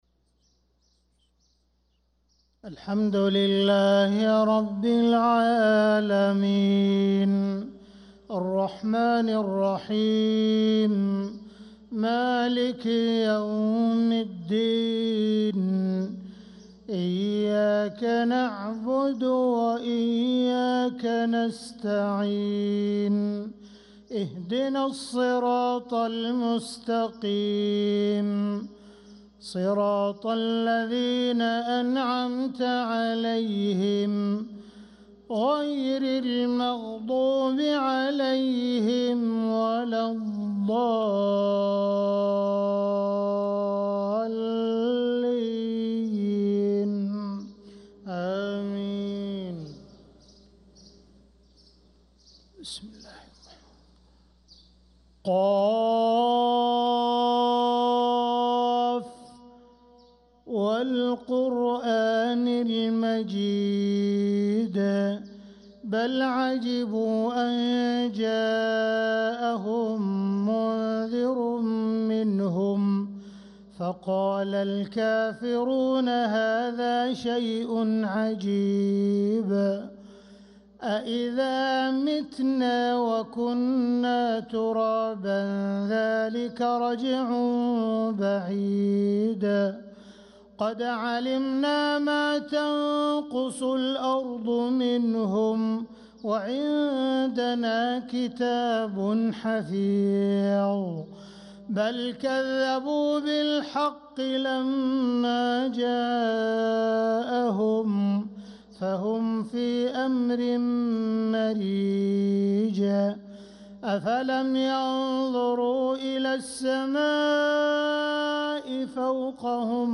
صلاة الفجر للقارئ عبدالرحمن السديس 19 رجب 1446 هـ
تِلَاوَات الْحَرَمَيْن .